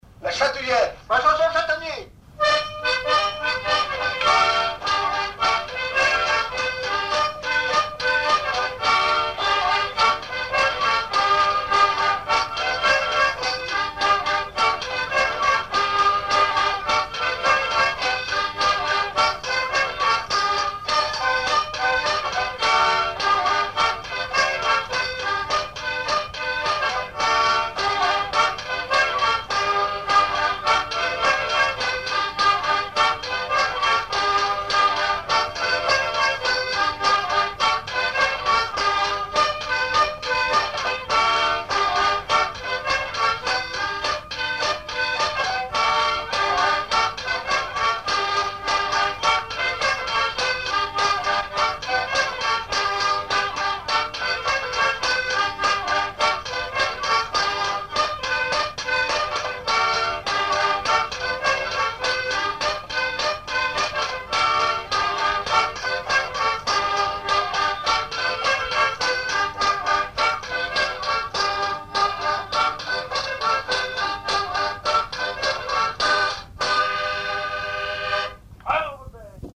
Chants brefs - A danser
danse : polka
enregistrements du Répertoire du violoneux
Pièce musicale inédite